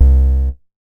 MoogAgressUp B.WAV